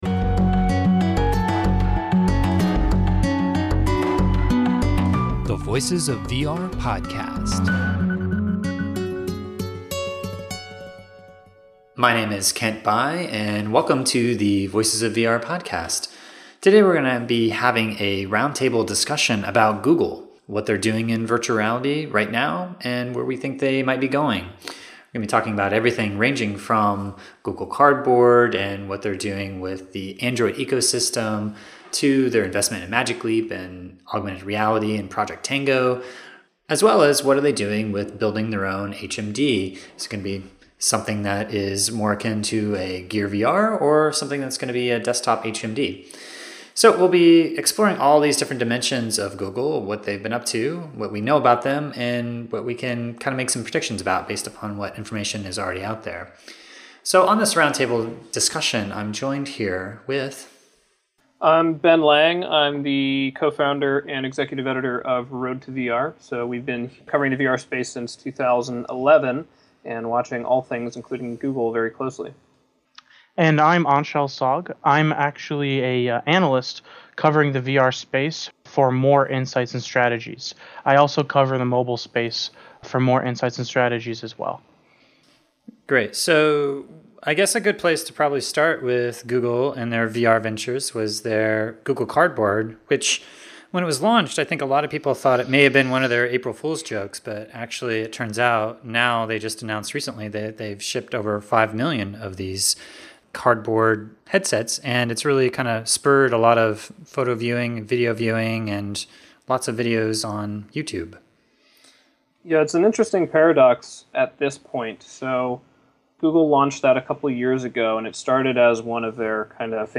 Voices-of-VR-304-Google-Roundtable.mp3